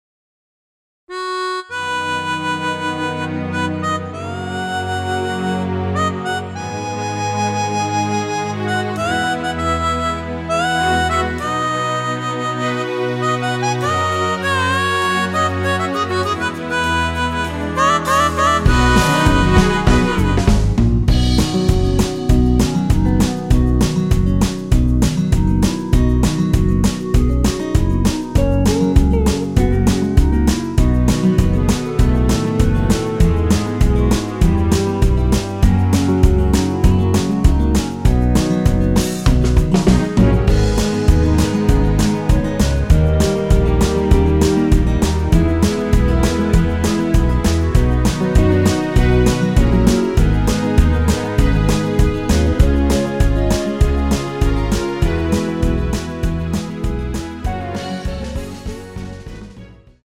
전주가 길어서 8마디로 편곡 하였으며
원키에서(-1)내린 (1절+후렴)으로 진행되는 멜로디 포함된 MR입니다.
◈ 곡명 옆 (-1)은 반음 내림, (+1)은 반음 올림 입니다.
앞부분30초, 뒷부분30초씩 편집해서 올려 드리고 있습니다.
중간에 음이 끈어지고 다시 나오는 이유는